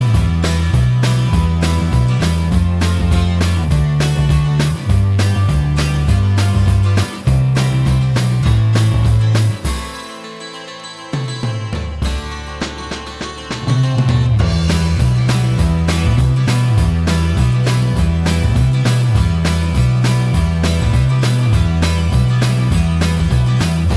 Minus Electrics Rock 2:49 Buy £1.50